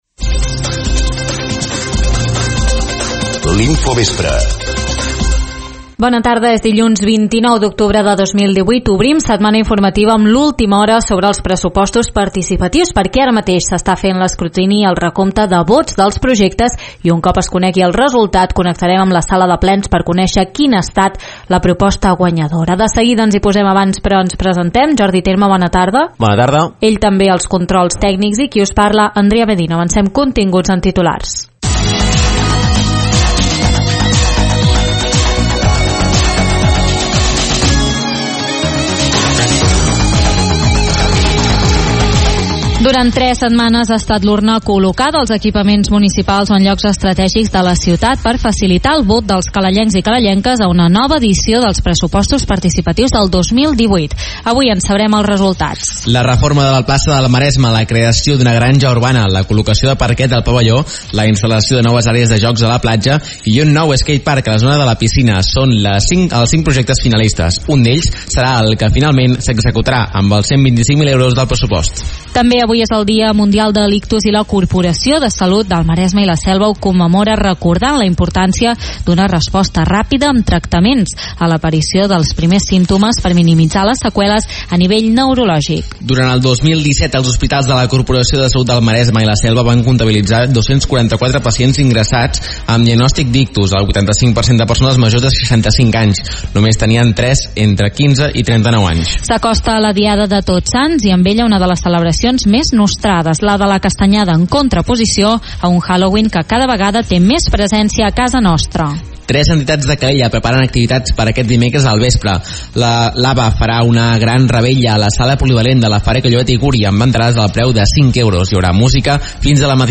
Obrim setmana informativa amb l’última hora sobre els Pressupostos Participatius, minuts després que es conegui el resultat connectem amb la sala de plens, on s’ha fet l’escrutini, per conèixer la proposta guanyadora, que ha estat la instal·lació de noves àrees de joc a la platja.